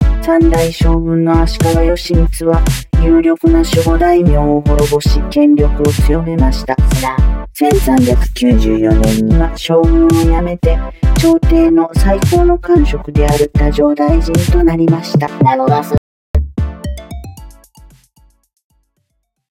録音した言葉にメロディをつけて歌にしてくれるスマホアプリ。平板な音声にメロディとリズムがのる。